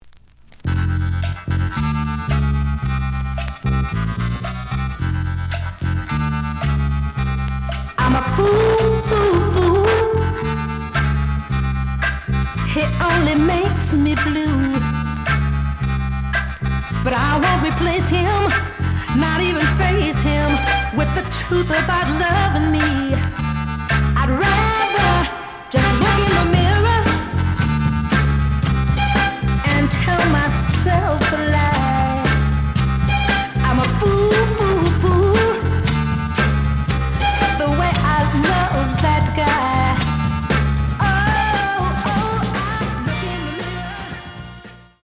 The audio sample is recorded from the actual item.
●Genre: Soul, 60's Soul
Slight noise on both sides.